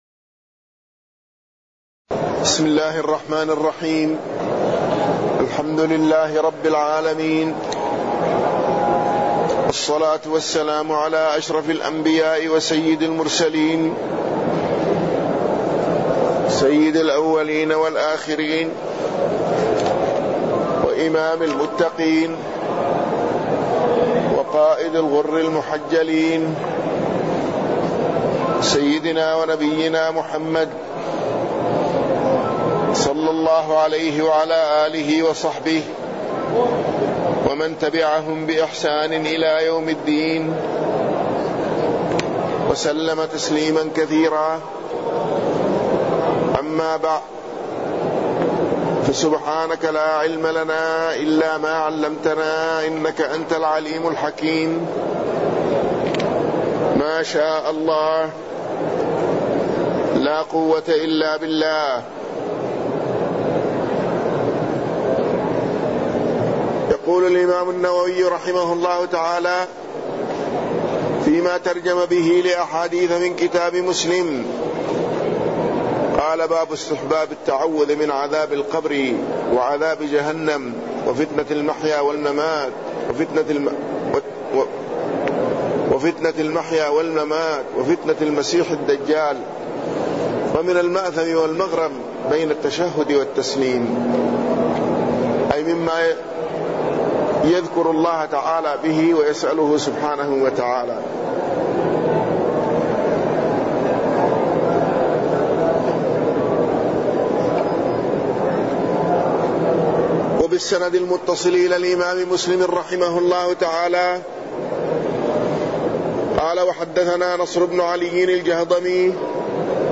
تاريخ النشر ١٧ شعبان ١٤٢٩ هـ المكان: المسجد النبوي الشيخ